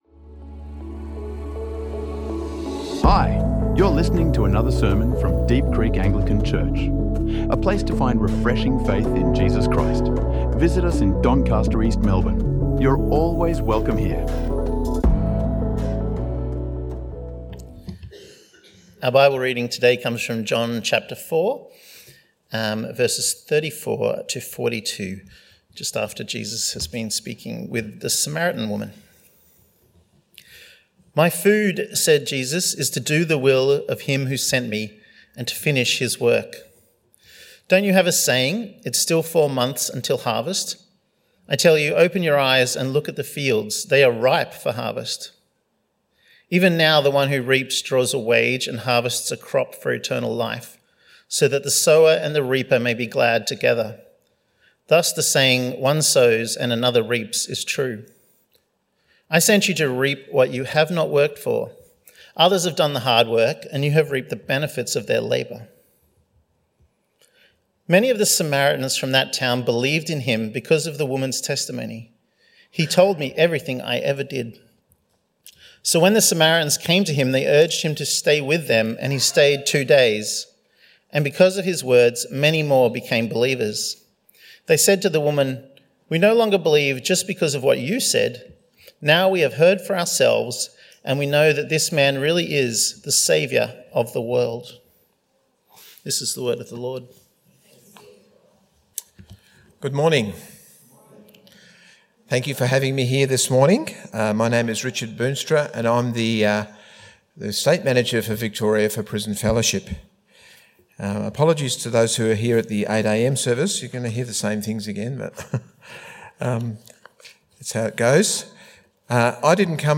Caring For Those in Prison | Sermons | Deep Creek Anglican Church